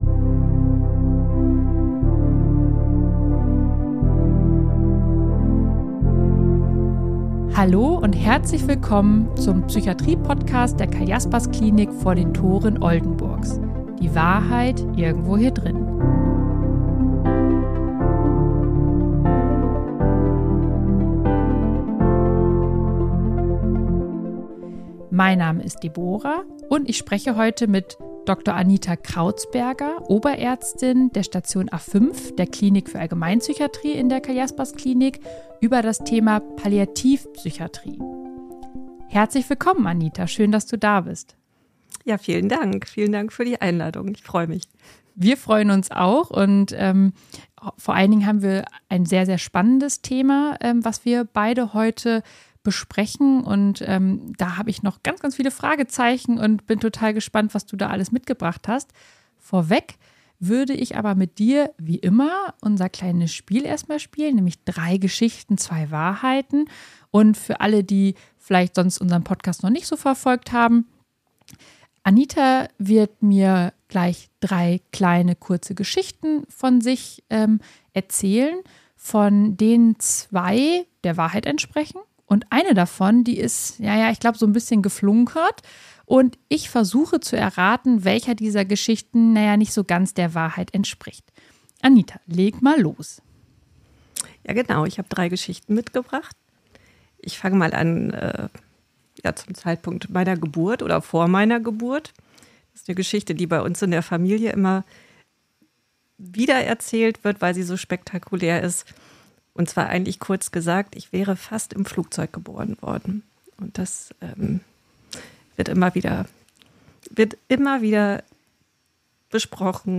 #23 PALLIATIV-PSYCHIATRIE Experten-Talk ~ Die Wahrheit Irgendwo Hier Drinnen Podcast